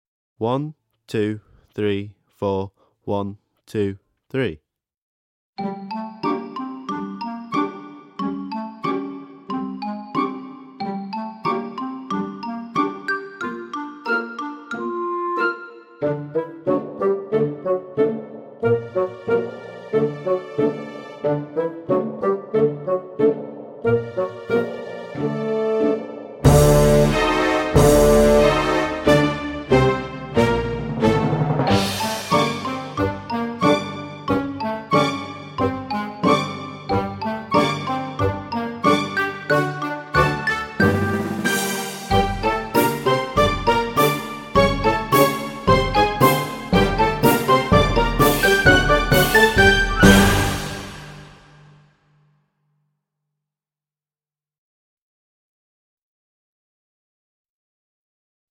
VS Trolls, Orcs and Goblins (backing track)